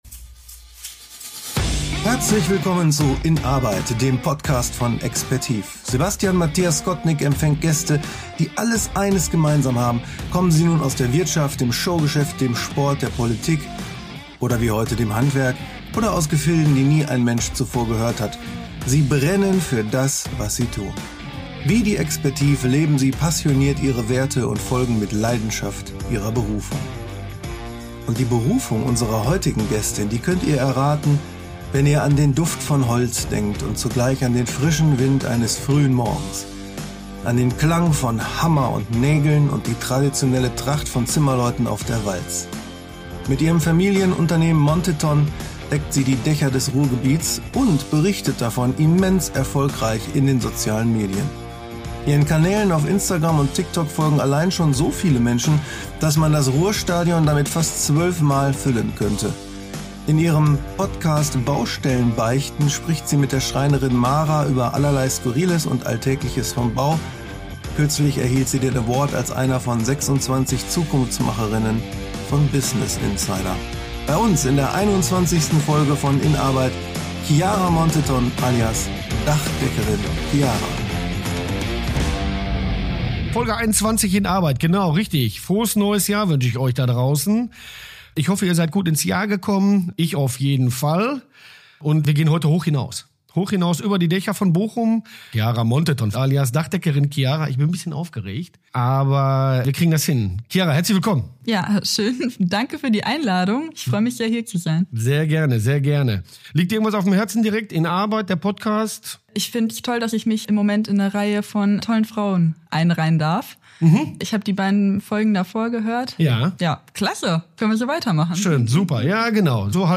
Ein Gespräch über faires Arbeiten, Schicksalswege und die Power spontaner Ideen.